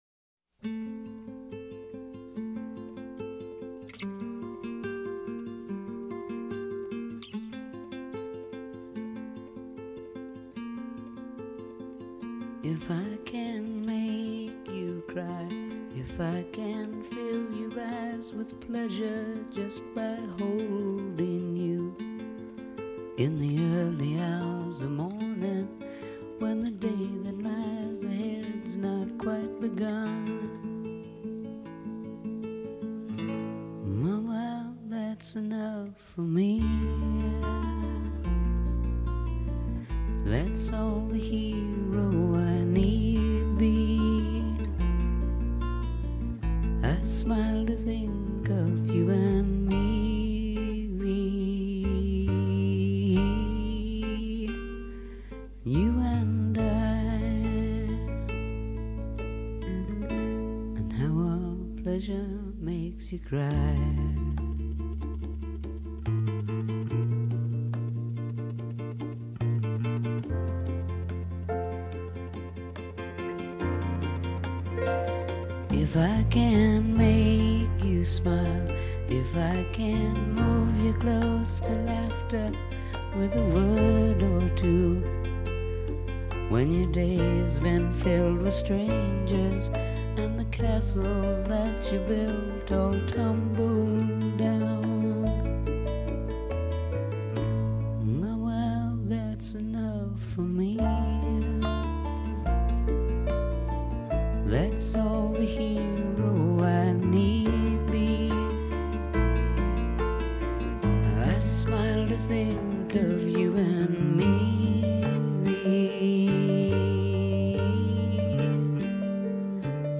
and it is a really good ballad.